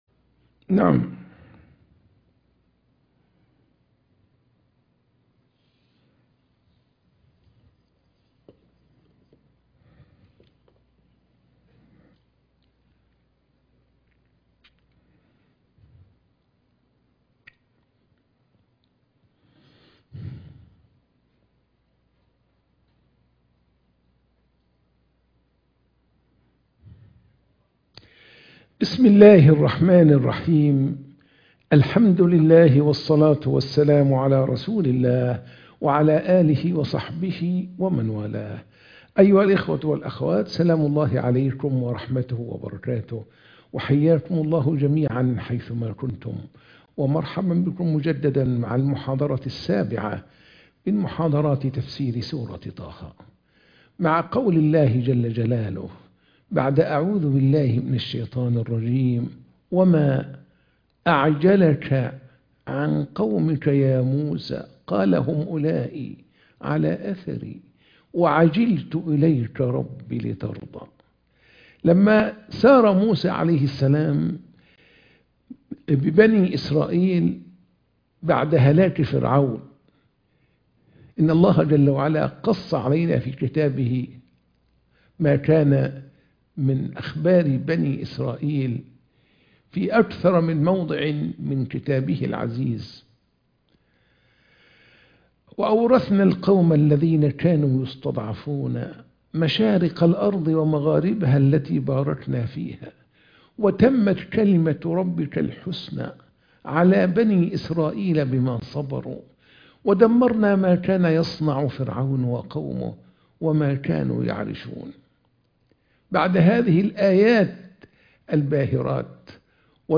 محاضرة التفسير - سورة طه - المحاضرة 7